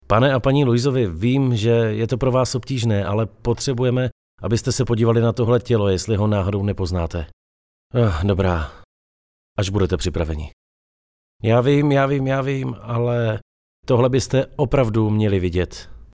Profesionální dabing - mužský hlas